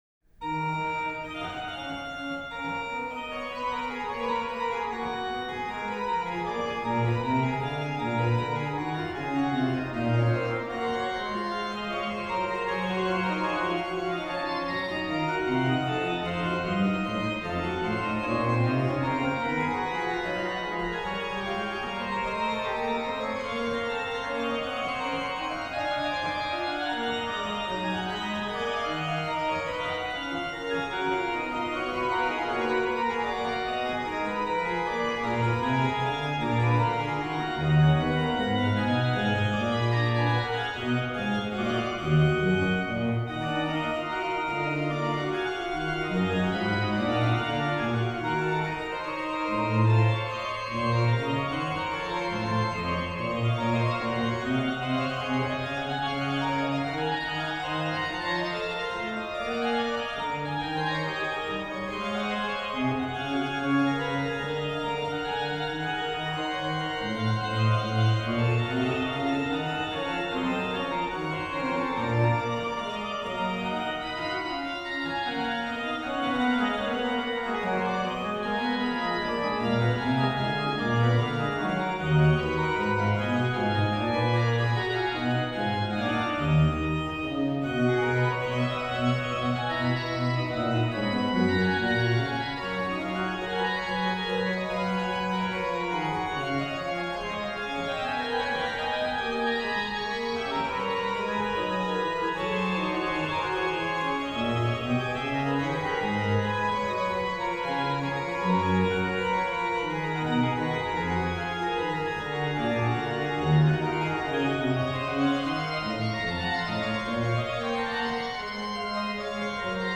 rh: BW: Ged8, Fl4, Oct2, Sesq
lh: HW: Gms8, Por8, Oct4, Qnt3, Oct2
Ped: Oct8, Tr8